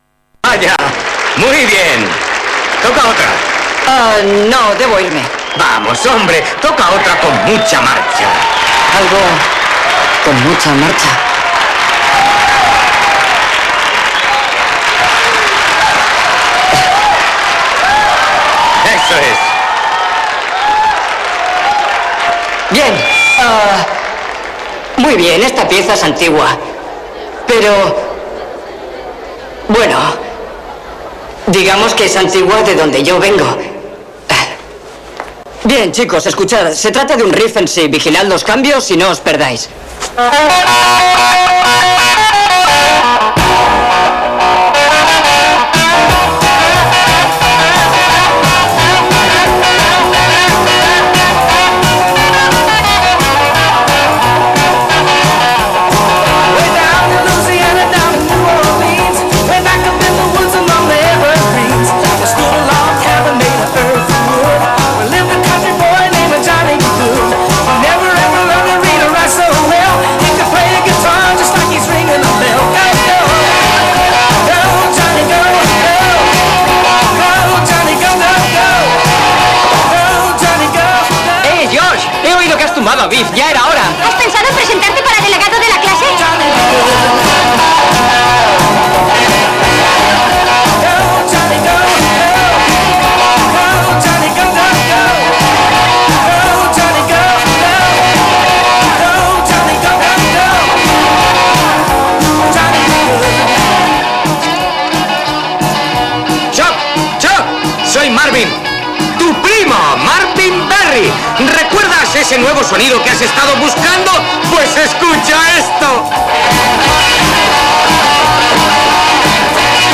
Música Heavy 70´s
heavy_70_s.mp3